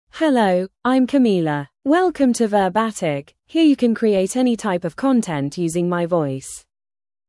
FemaleEnglish (United Kingdom)
Camila is a female AI voice for English (United Kingdom).
Voice sample
Camila delivers clear pronunciation with authentic United Kingdom English intonation, making your content sound professionally produced.